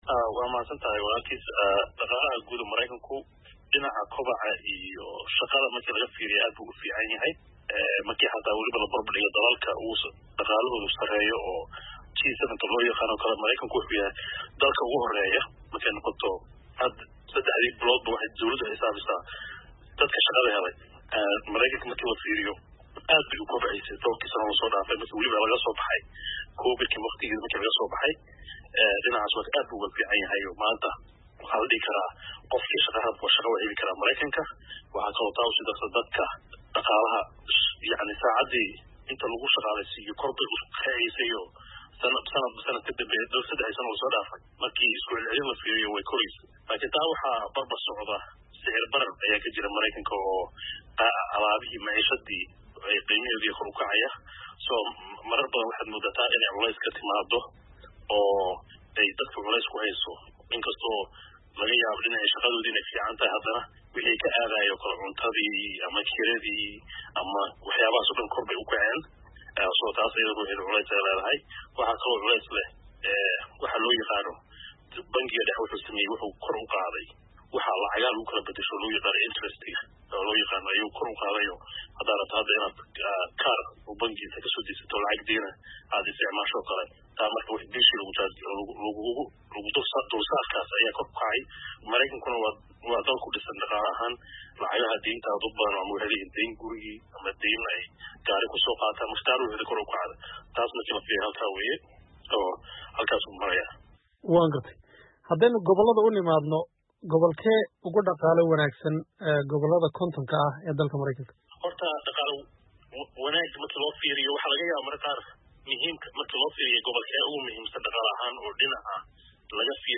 Wareysi: Xaaladda dhaqaalaha Mareykanka